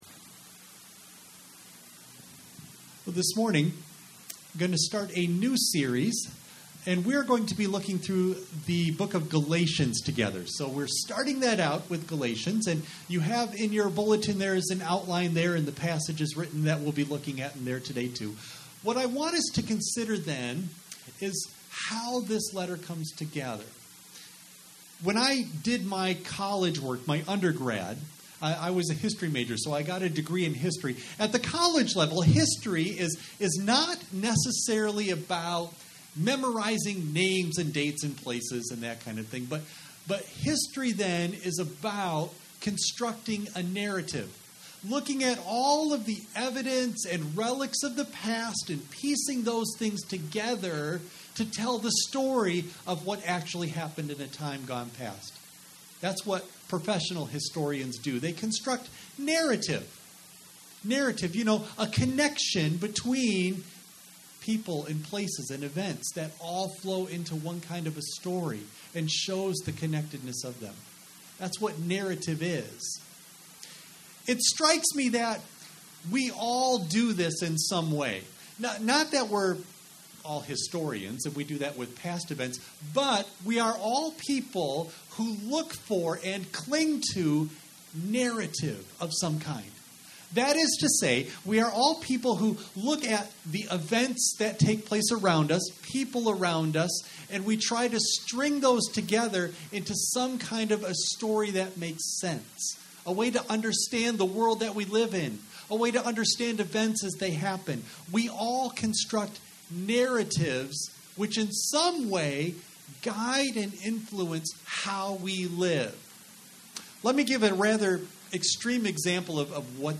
You may download and print the BULLETIN for this service as well as sermon NOTES for children from the Download Files section at the bottom of this page Worship Service September 6 Audio only of message